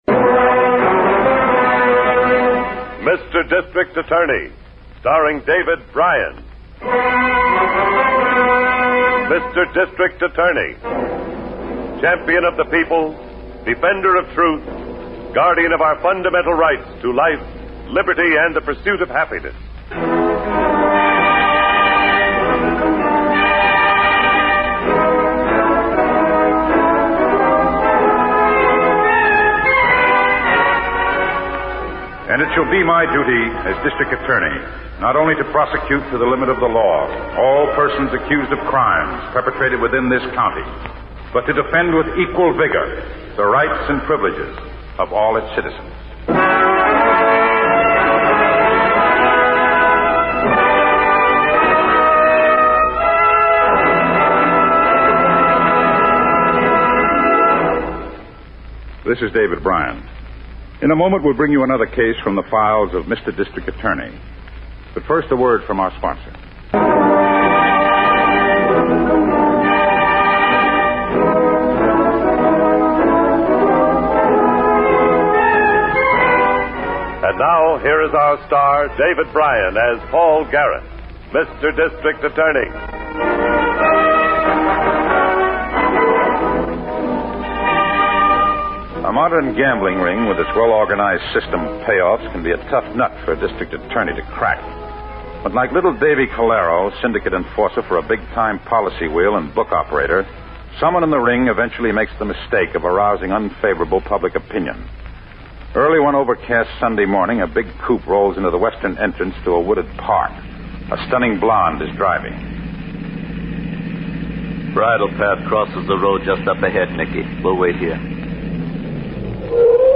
District Attorney is a radio crime drama, produced by Samuel Bischoff, which aired on NBC and ABC from April 3, 1939, to June 13, 1952 (and in transcribed syndication through 1953).